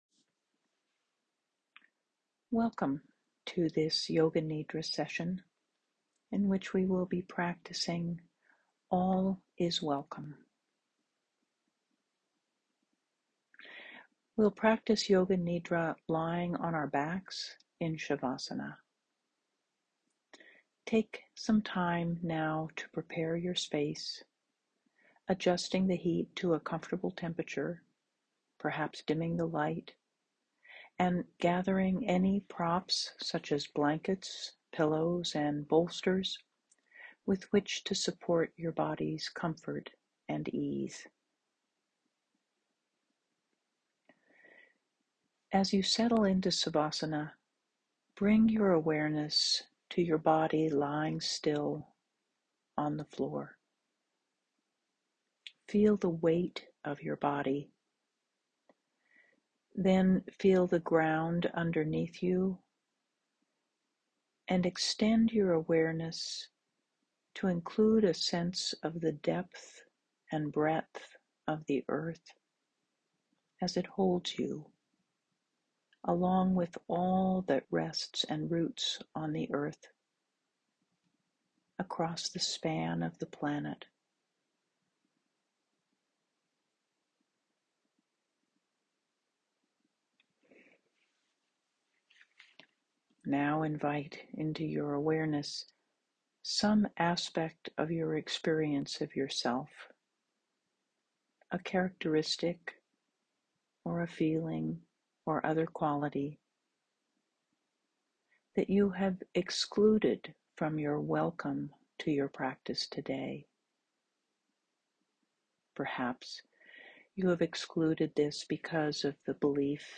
Yoga Nidra “Welcoming All” practice.m4a